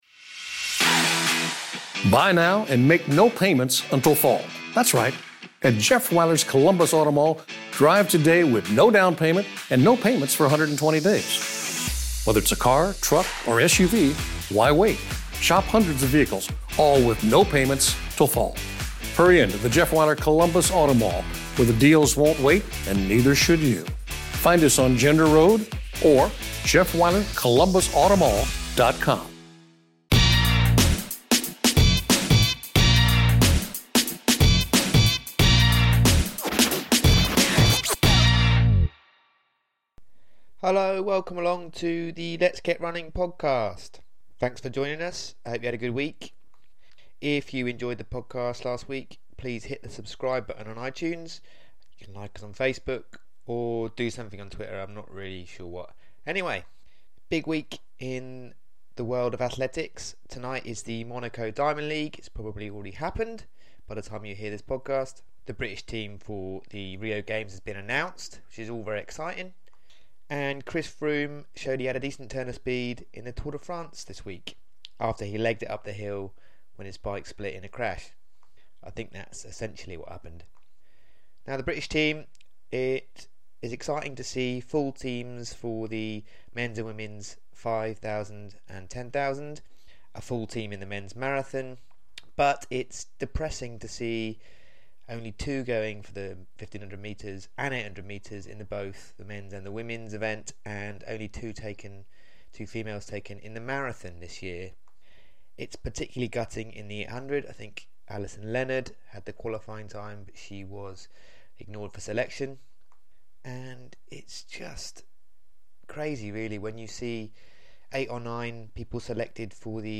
This week we talk to British 5000m champion and Rio bound runner Andy Butchart. We discuss breaking Scottish records, his track breakthrough this year, his racing mindset, and training at the GB altitude camp in Font Romeu.